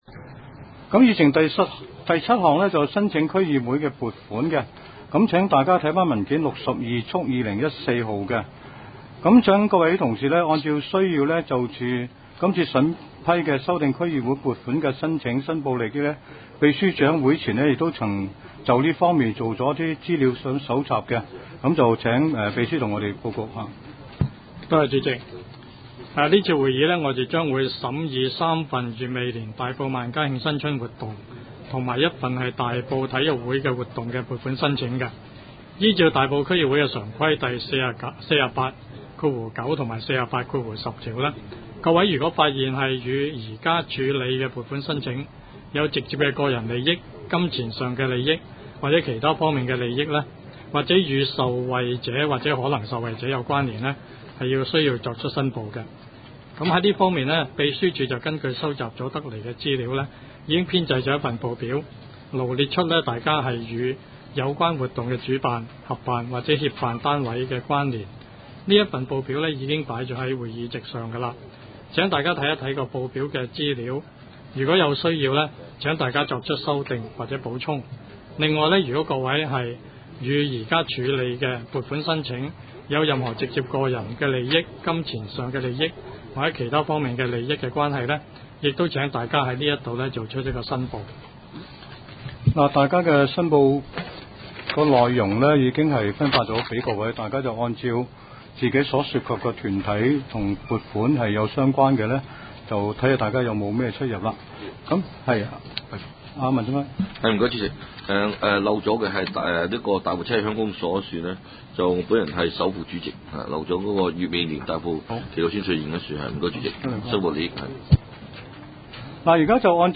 区议会大会的录音记录
大埔区议会秘书处会议室